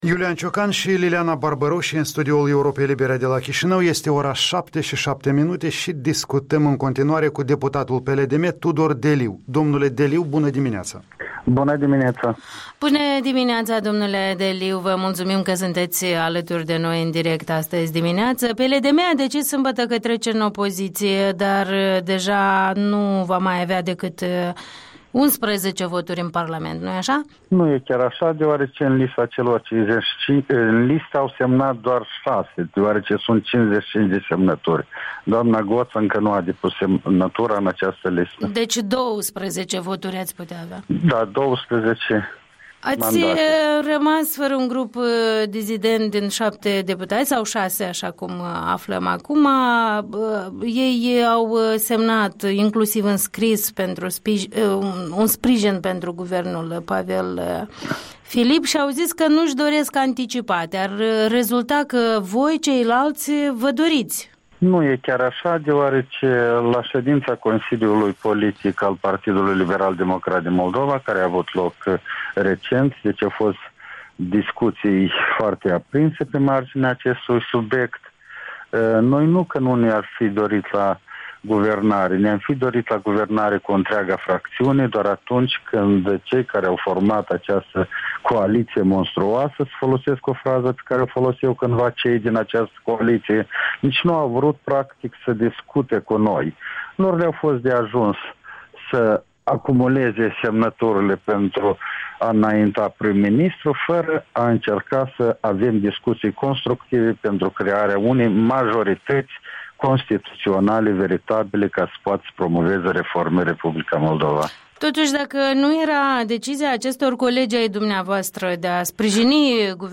Interviul dimineții cu un jurist deputat PLDM.